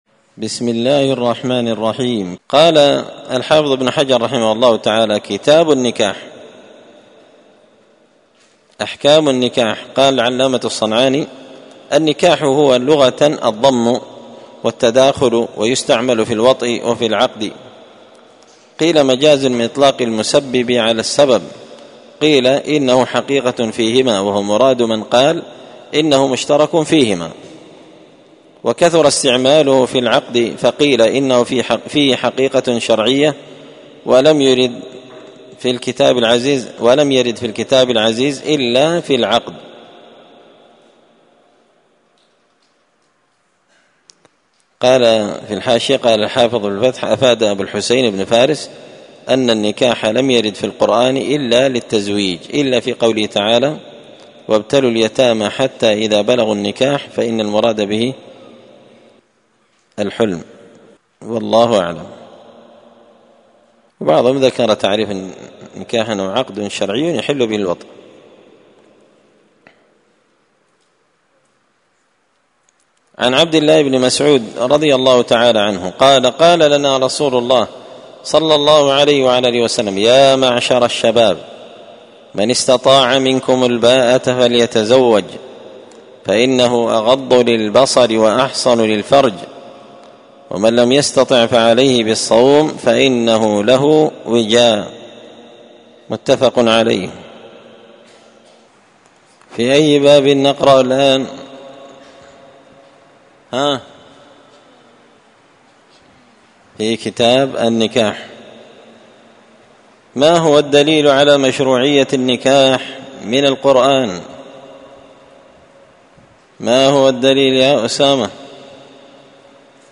كتاب النكاح من سبل السلام شرح بلوغ المرام لابن الأمير الصنعاني رحمه الله تعالى الدرس – 1 أحكام النكاح
مسجد الفرقان_قشن_المهرة_اليمن